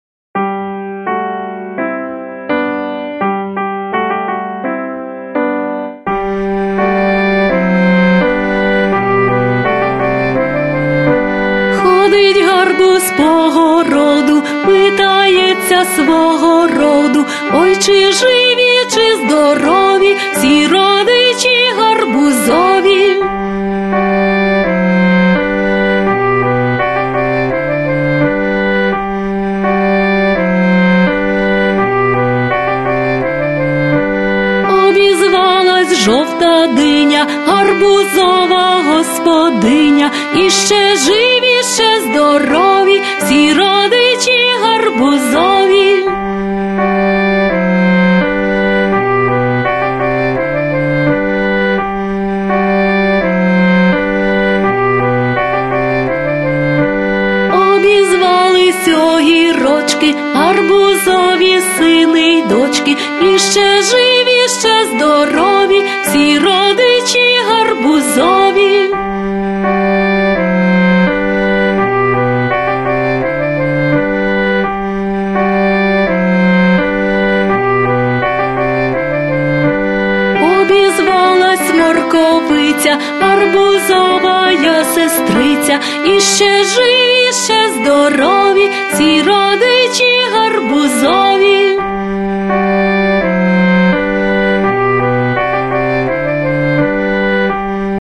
Яка весела пісенька!